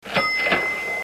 CH-CHING